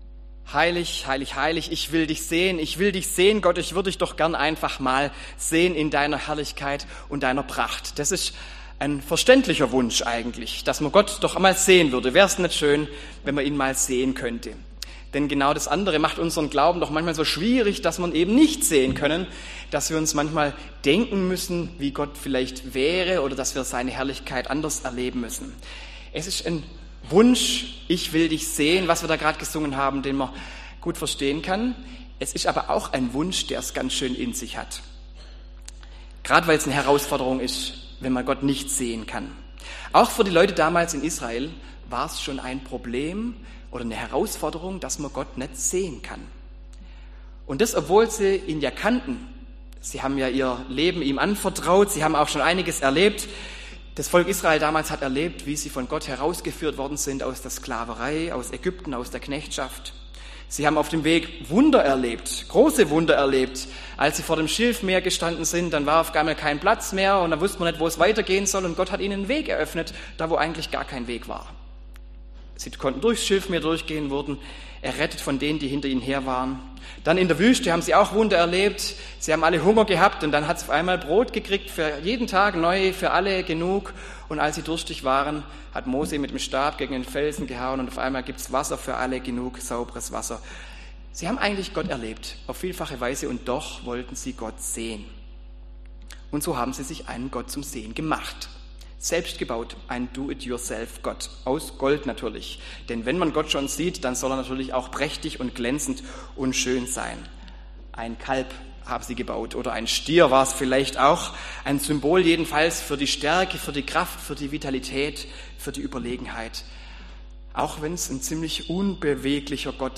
Predigt im Gottesdienst am 2. Sonntag nach Epiphanias